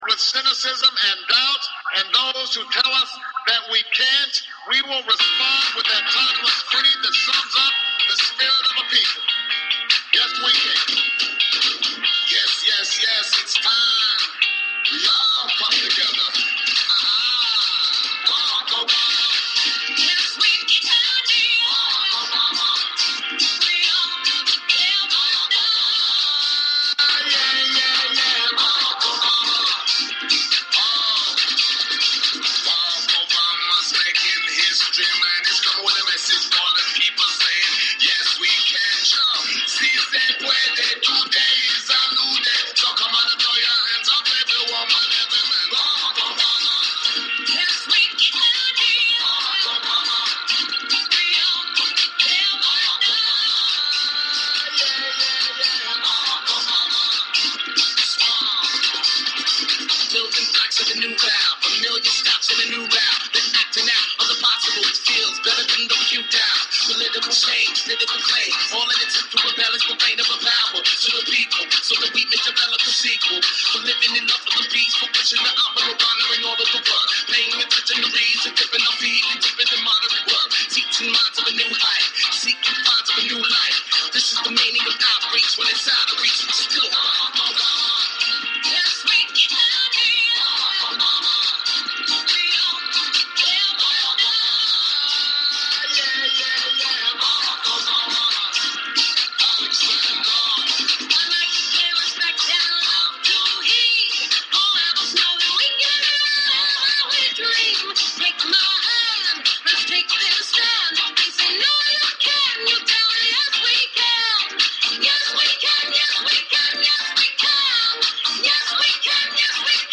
Talk Show Episode, Audio Podcast, Galactic_Roundtable and Courtesy of BBS Radio on , show guests , about , categorized as